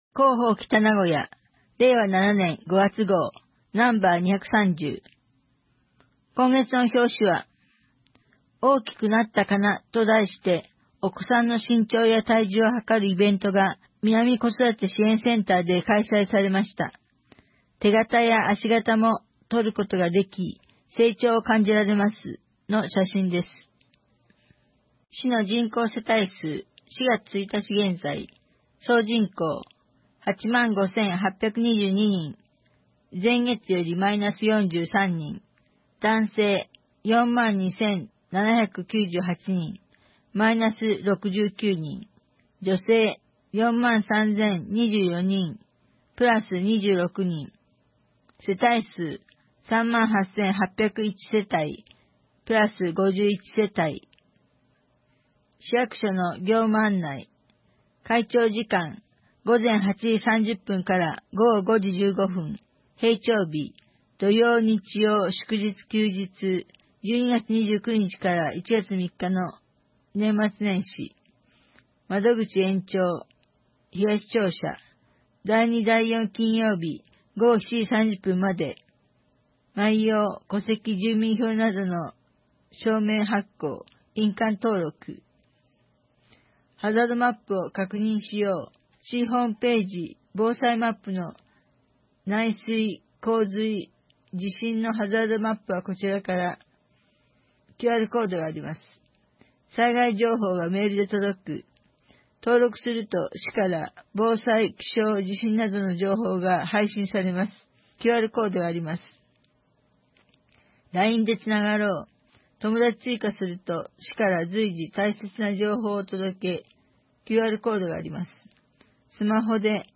2025年5月号「広報北名古屋」音声版